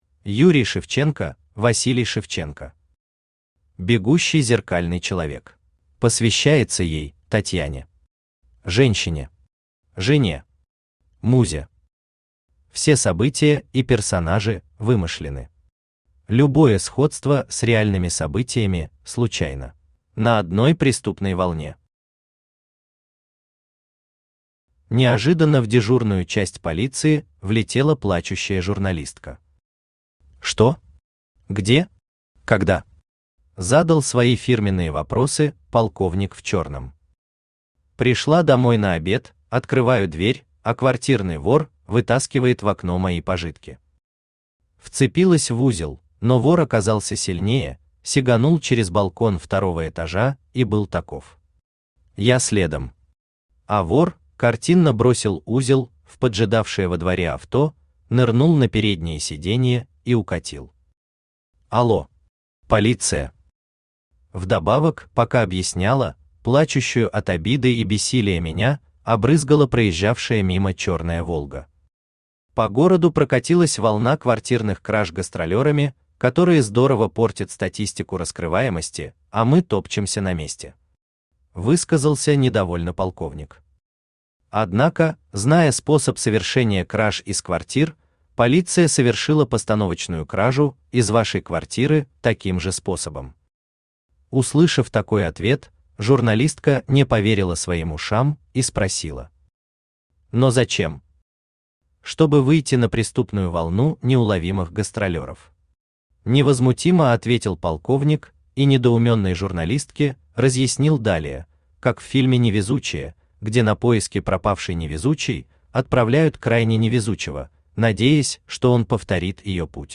Аудиокнига Бегущий зеркальный человек | Библиотека аудиокниг
Aудиокнига Бегущий зеркальный человек Автор Юрий Павлович Шевченко Читает аудиокнигу Авточтец ЛитРес.